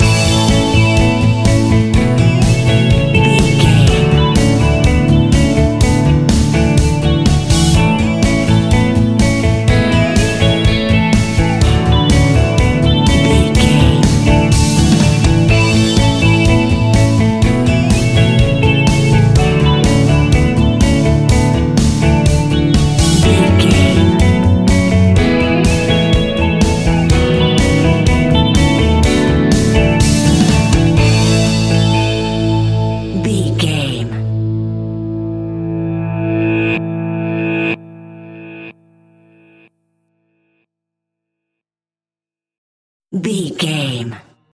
Uplifting
Ionian/Major
pop rock
indie pop
fun
energetic
cheesy
instrumentals
guitars
bass
drums
piano
organ